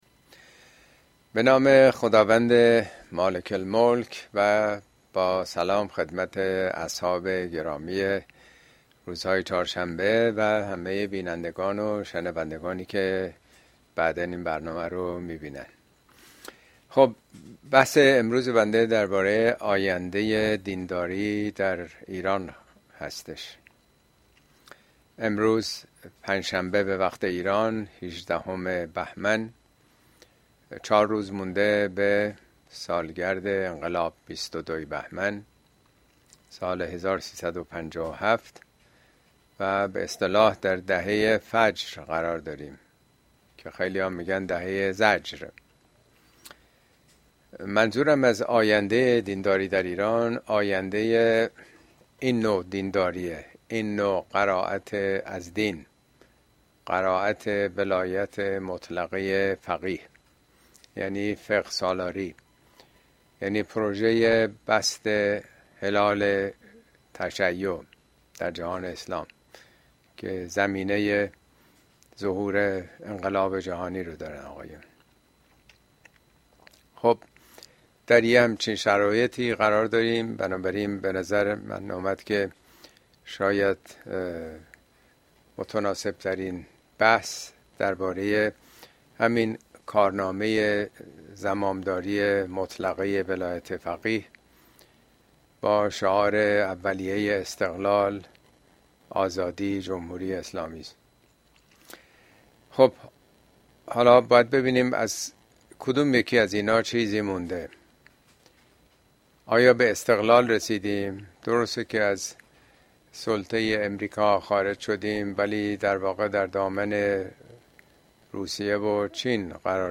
Speech
` موضوعات اجتماعى اسلامى آینده دینداری در ایران اين سخنرانى به تاريخ ۵ فوریه ۲۰۲۵ در كلاس آنلاين پخش شده است توصيه ميشود براىاستماع سخنرانى از گزينه STREAM استفاده كنيد.